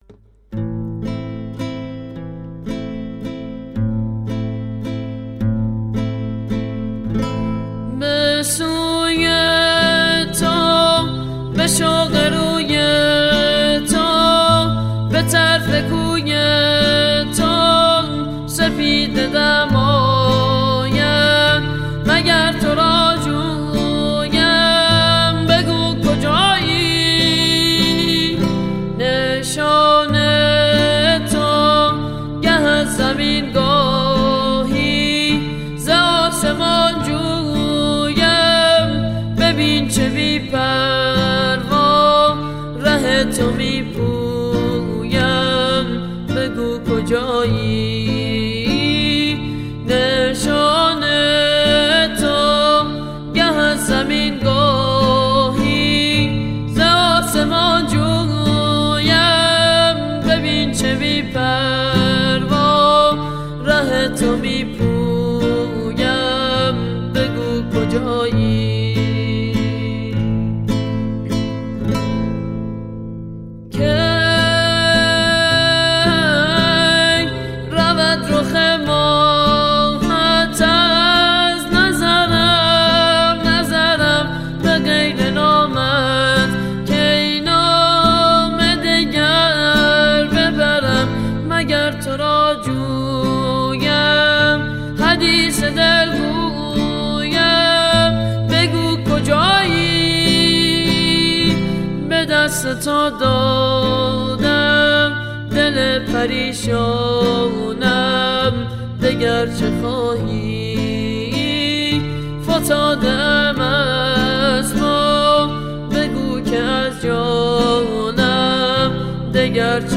پاپ سنتی
دانلود آهنگ غم انگیز